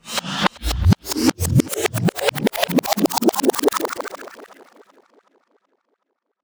VEC3 Reverse FX
VEC3 FX Reverse 57.wav